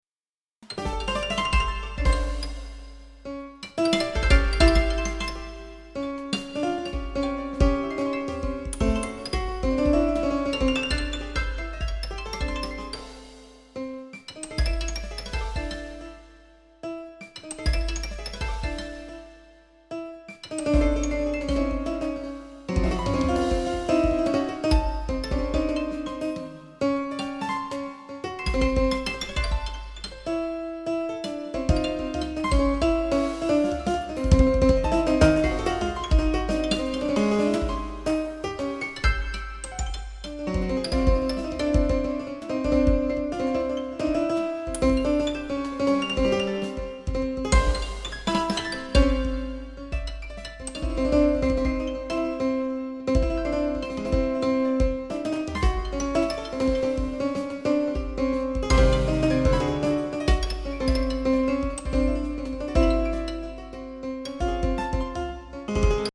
Sour: high pitch, short duration, high dissonance